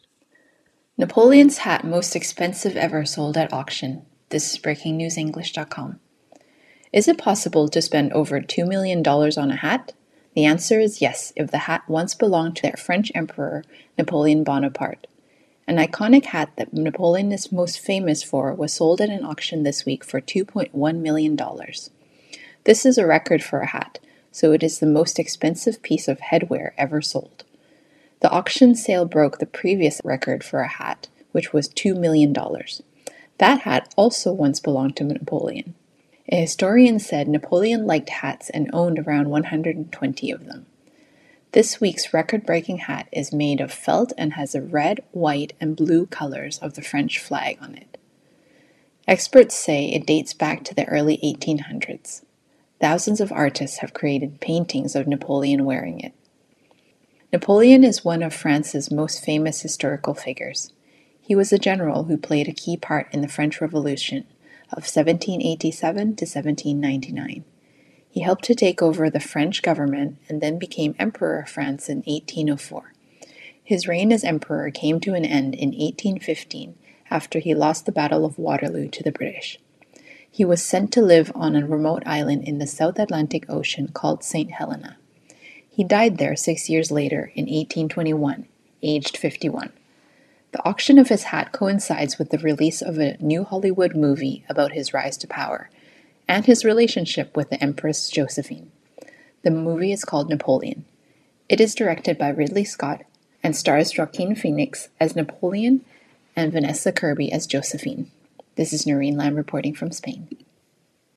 AUDIO(Normal)